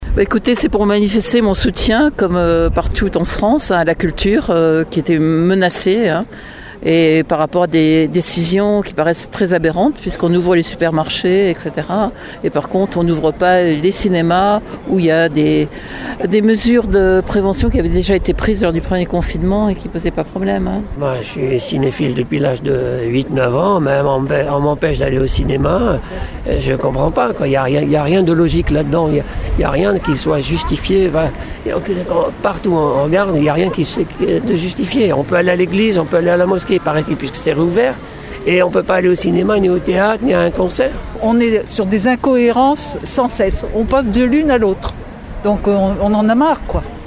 Une centaine de personnes rassemblées hier devant le Relais de la côte de beauté à Saint-Georges-de-Didonne.
Parmi les manifestants : des salariés et des bénévoles du Créa, mais aussi des habitués qui nous ont fait part de leur mécontentement et de leur incompréhension :